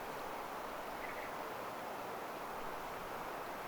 harakan ääni